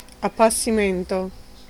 Ääntäminen
Ääntäminen US : IPA : [feɪ.dɪŋ]